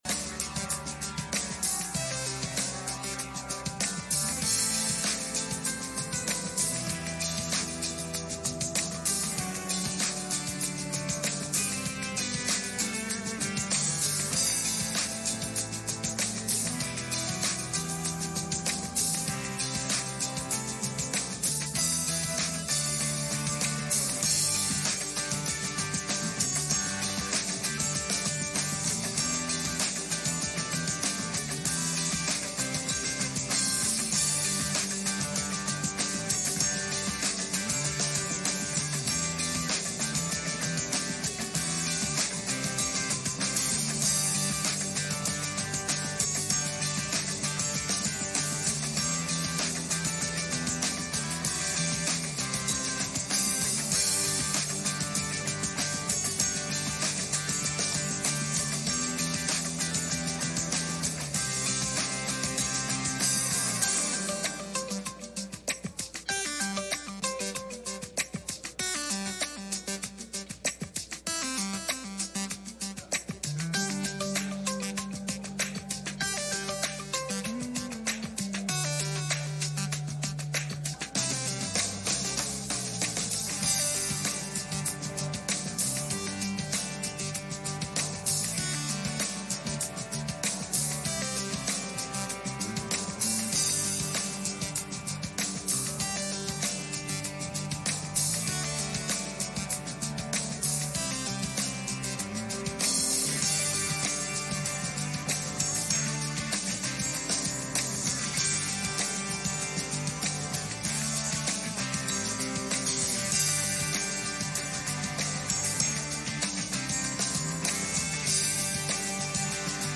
1 Samuel 25:2-3 Service Type: Sunday Morning « Paul’s Four Up’s For Timothy